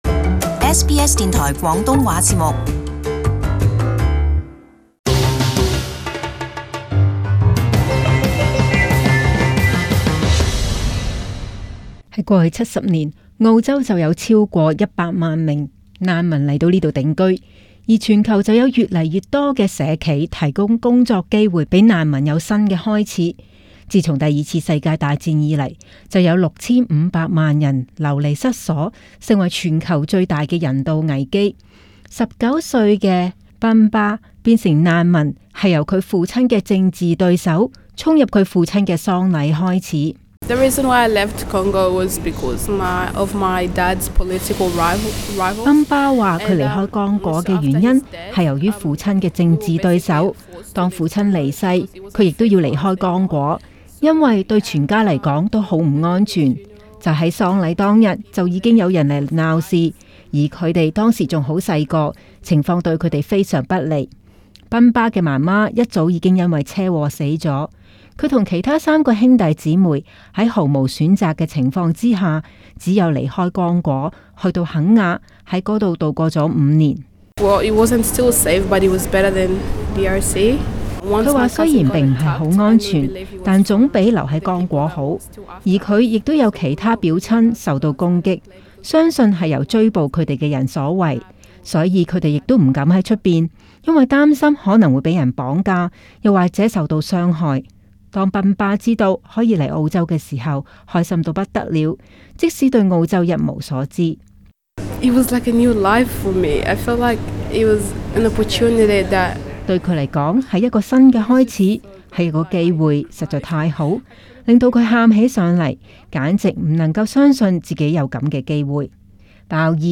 【時事報導】社企造福社會